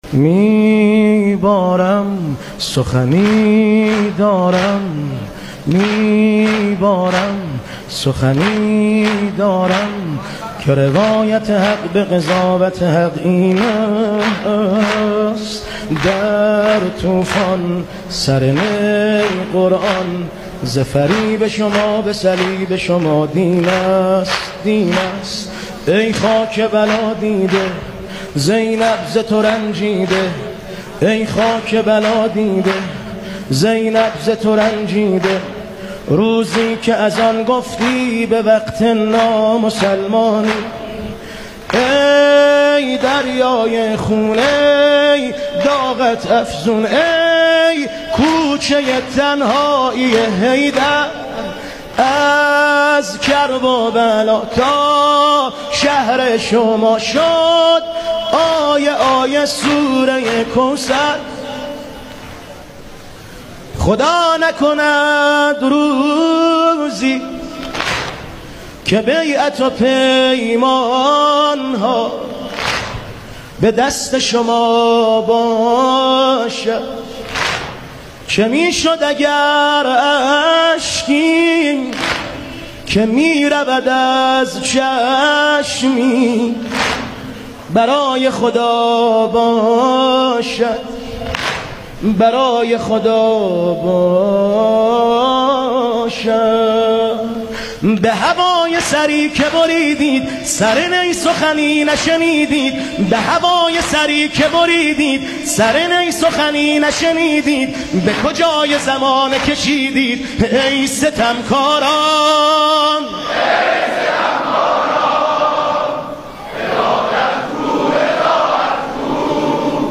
صوت | عزاداری هیئت پنبه‌کاران یزد در روز عاشورا